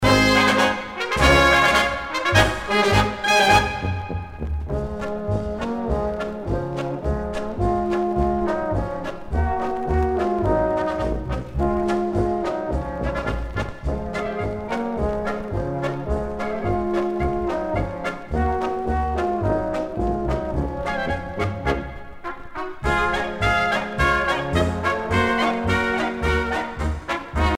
danse : marche ; danse : marche-polka ;
Pièce musicale éditée